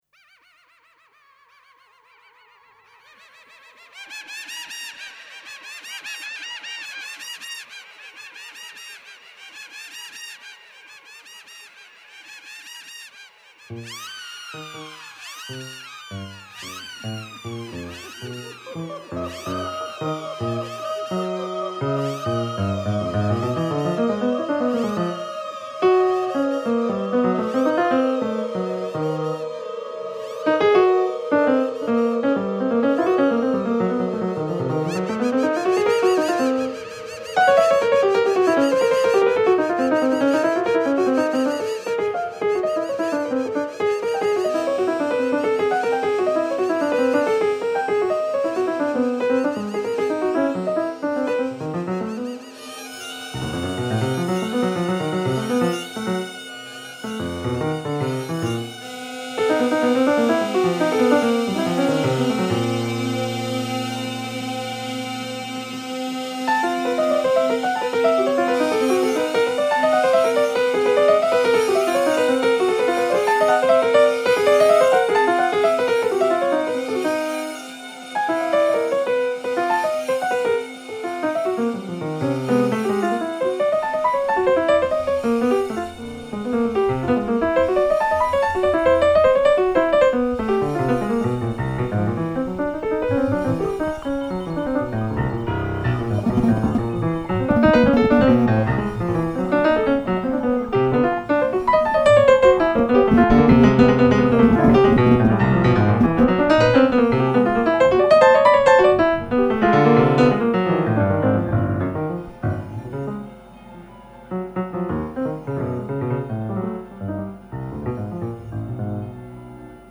Le 30 mars 2017 à l'EnCours